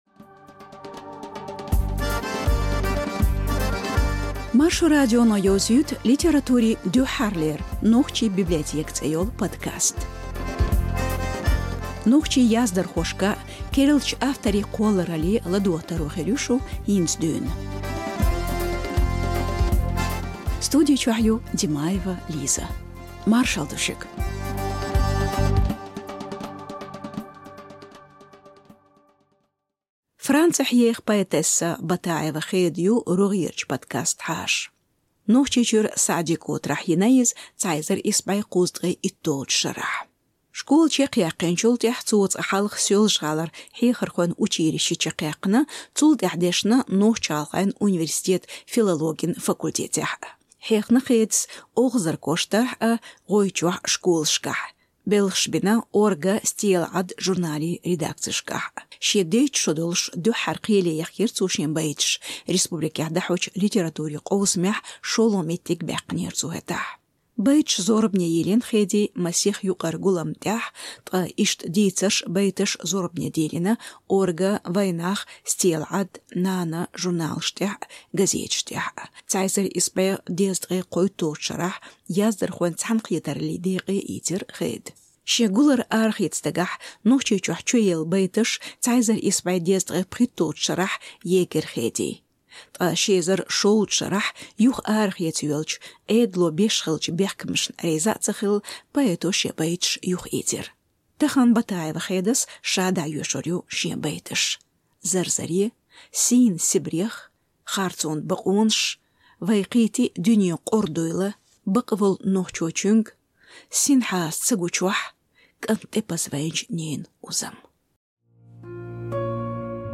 цуо ша дIайешна цуьнан байташ а.